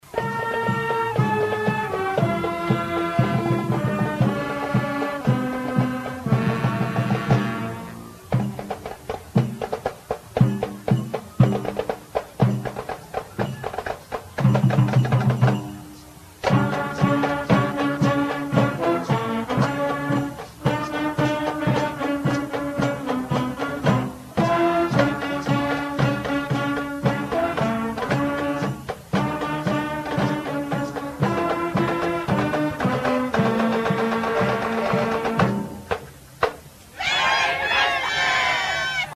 And the parade included the Trigg County Middle School Band.